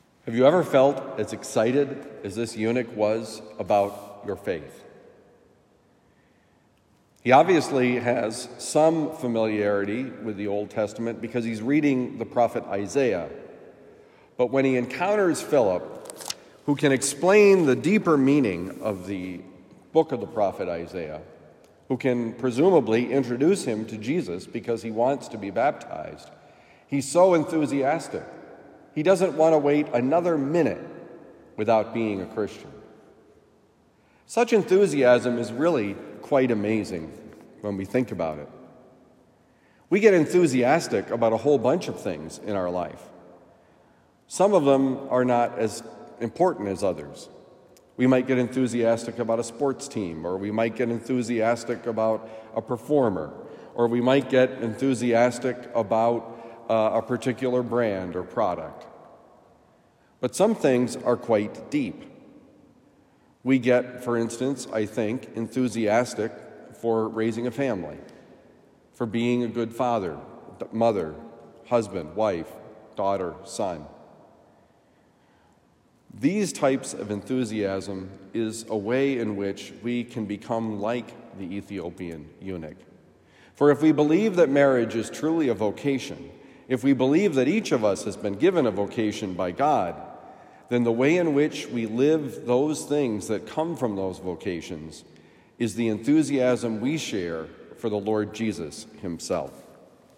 Enthusiasm for God: Homily for Thursday, April 27, 2023
Given at Christian Brothers College High School, Town and Country, Missouri.